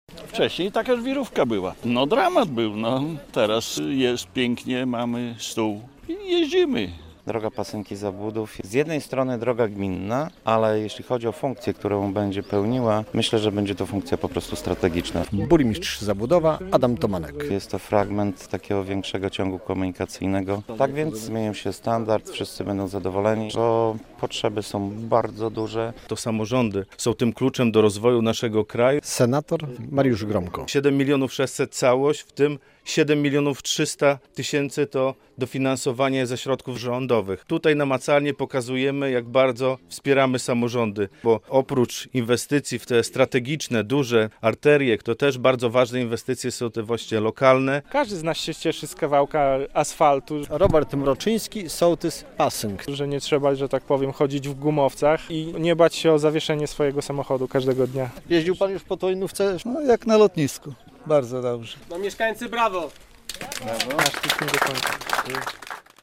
Oficjalne otwarcie wyremontowanej drogi we wsi Pasynki w gminie Zabłudów - relacja